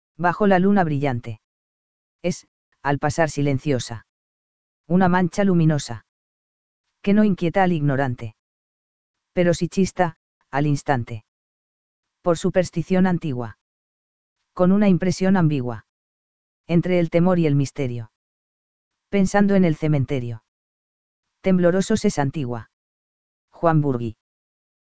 Llamado: emite un chillido desde una percha o en vuelo.
Lechuza de campanario2.mp3